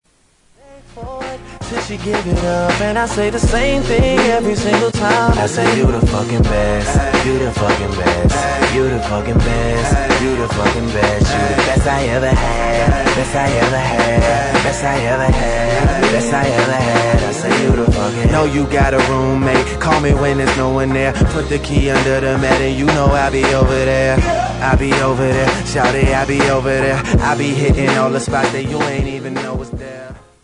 • Rap Ringtones